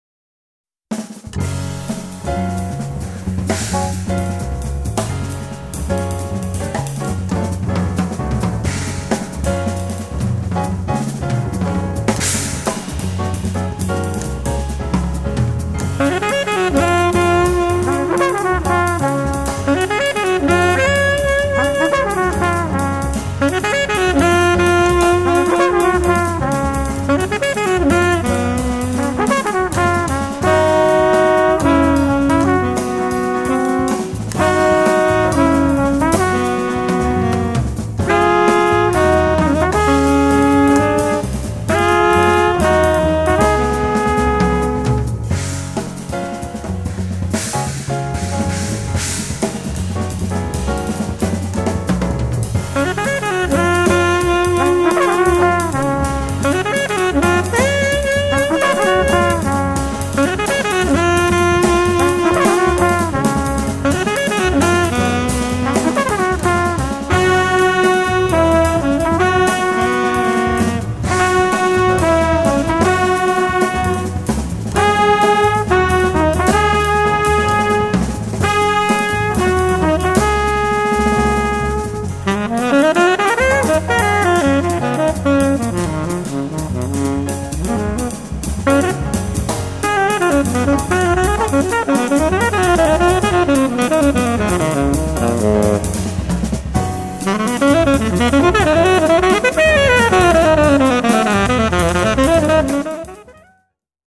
tenor saxophone
trumpet
piano
bass
drums